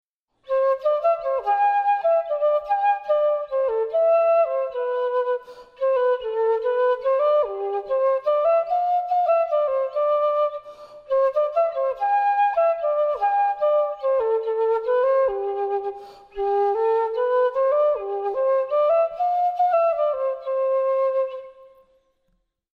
» バンブーアルケミーの横笛の音(concert D)
「ただの竹からこんなに美しい音色の笛ができるなんて本当に錬金術だ。」と評されたバンブーアルケミーの横笛です。
一応コンサートピッチなので、ピアノなどコンサート楽器と合奏できます。